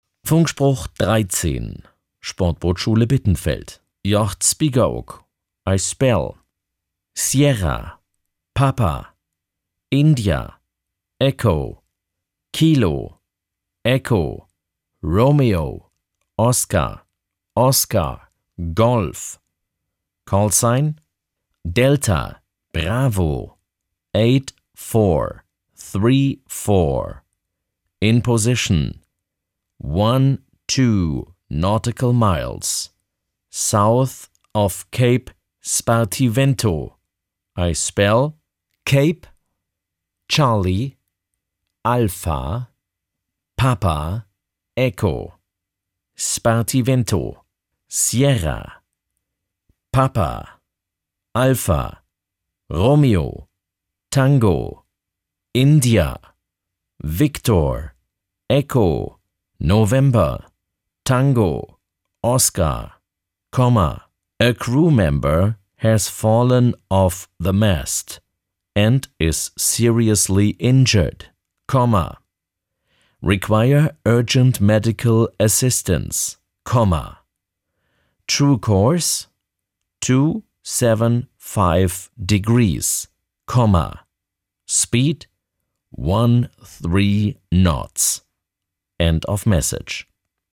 Funkspruch 13 – Sportbootschule Bittenfeld
Funkspruch-13.mp3